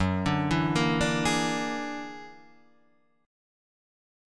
F#+9 Chord
Listen to F#+9 strummed